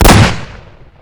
shotgunShoot.ogg